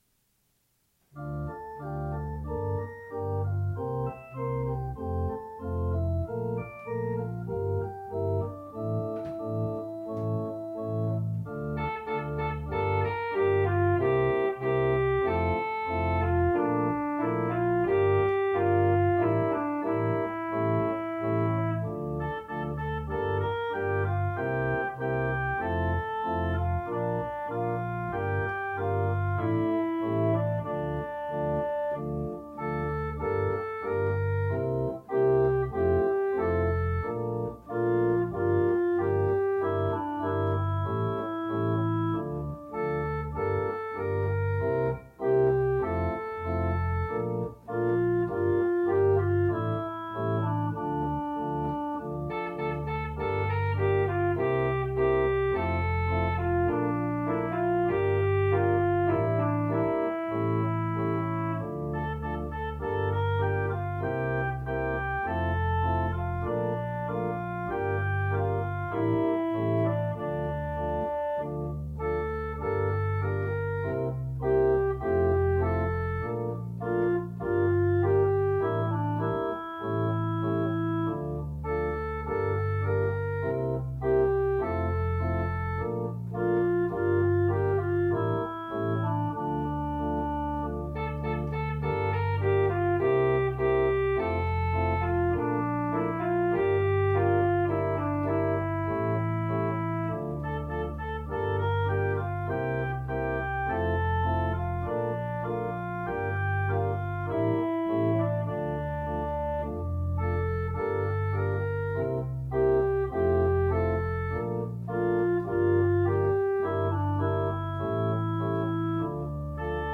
Beide Lieder habe ich auf vier Manualen gespielt und konnte so die Klangfarben ohne Unterbrechung wechseln.